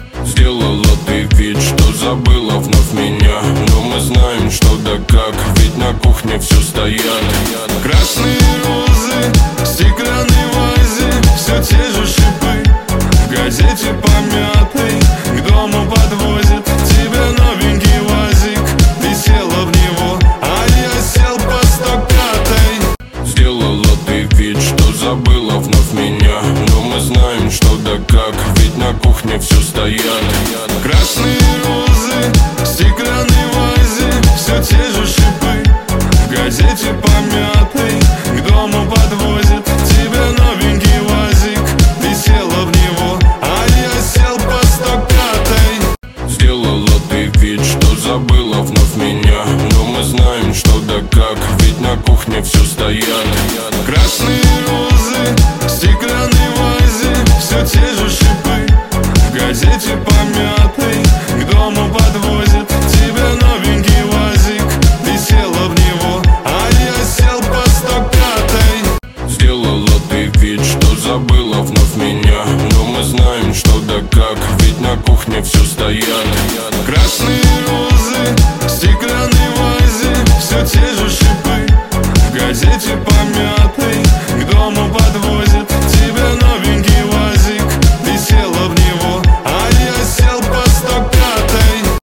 • Качество: 320, Stereo
спокойные
Нейросеть